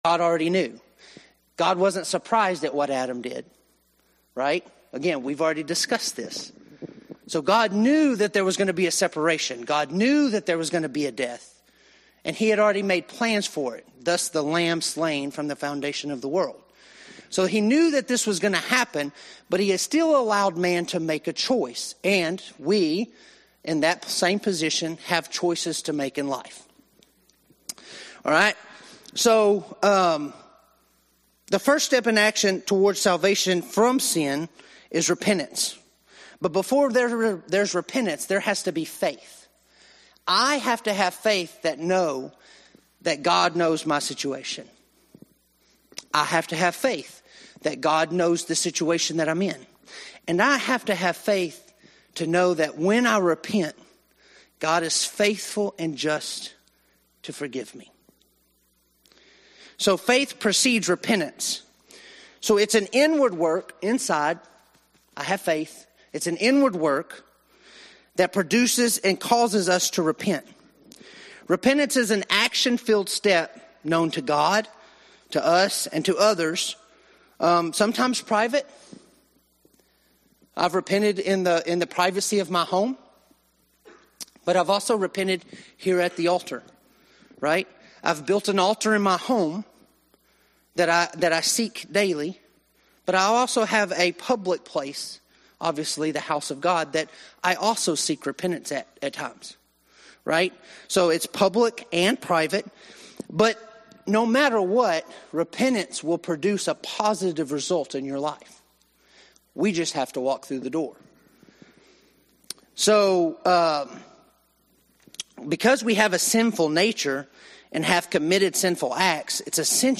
Adult Sunday School February 2026